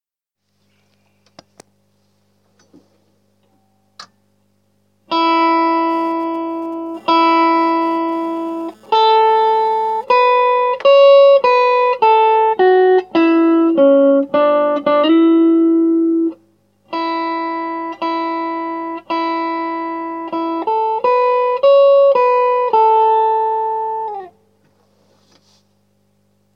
Mein alter Verstärker gab MANCHMAL () einen zweiten "verstimmten" Ton von sich...
Es klingt wie ein zu arg eingestellter Choruseffekt. Ich fahre nur gute Git- gutes Kabel- Amp Keine Effekte...